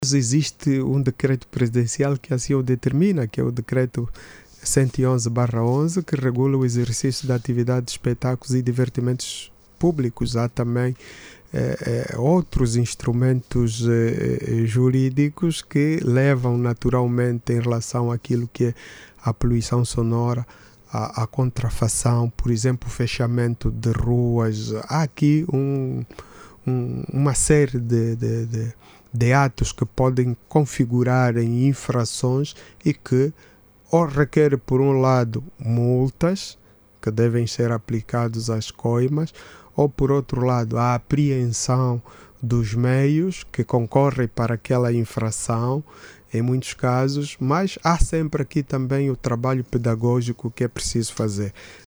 Entretanto o Vice-Governador de Luanda para o Sector Político-Social, lembrou que para as festas, bailes, e espectaculos, os promotores devem pedir autorização, sob pena de enfretarem acções judiciais. Manuel Gonçalves diz que para os casos de festas, bailes e espectaculos públicos ilegais, o Estado vai despoletar as sanções previstas no decreto presidencial e outra legislação.